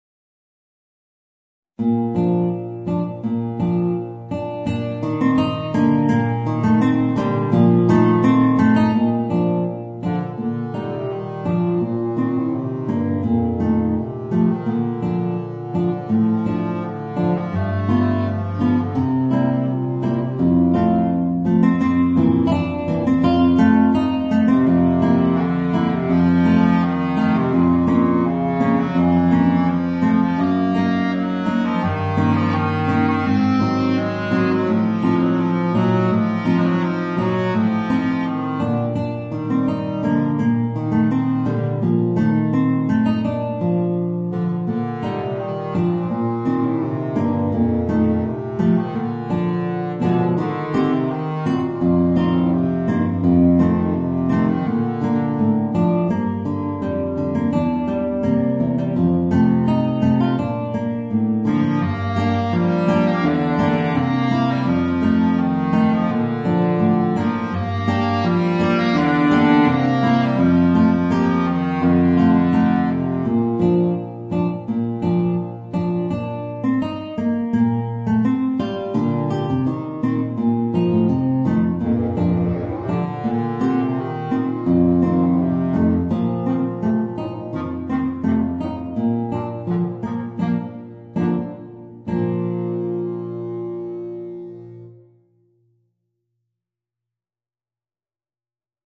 Bassklarinette & Gitarre